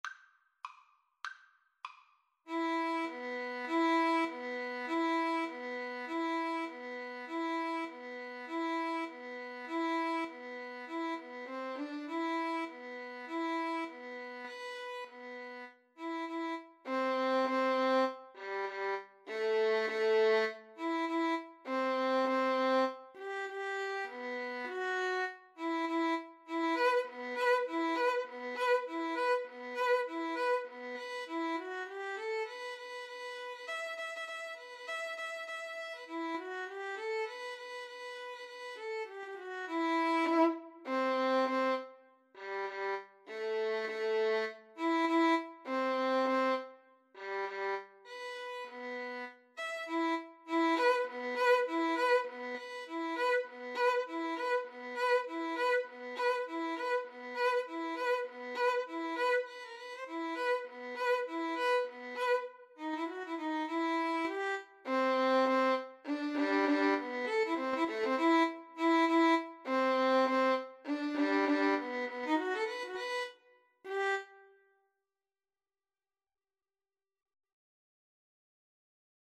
E minor (Sounding Pitch) (View more E minor Music for Violin Duet )
Firmly, with a heart of oak! Swung = c.100